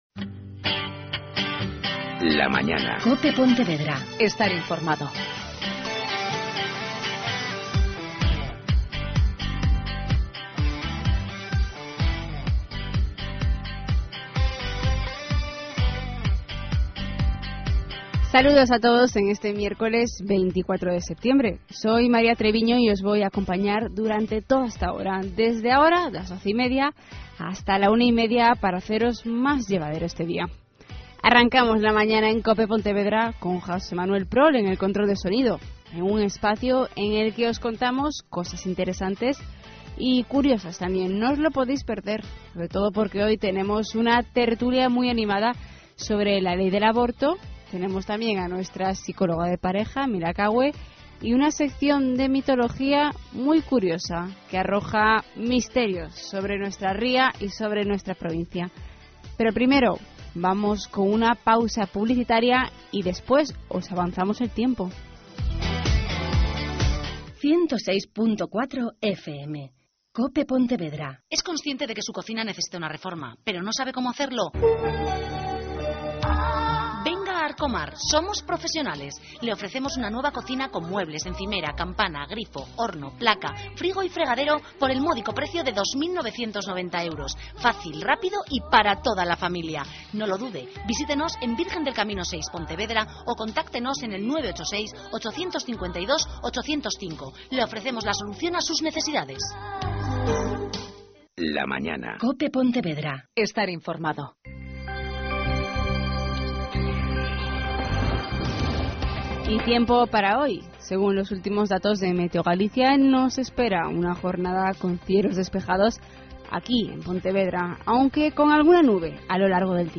Mi intervención abarca desde el minuto 00:06:00 hasta el 00:22:10